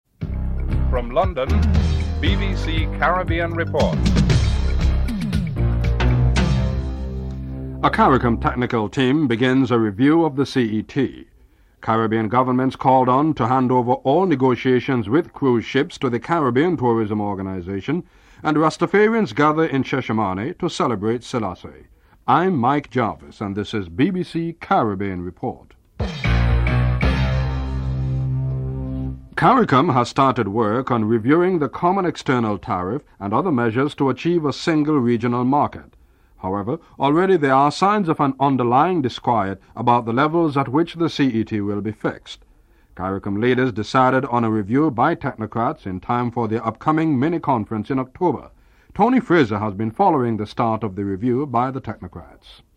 The British Broadcasting Corporation
1. Headlines (00:00-00:28)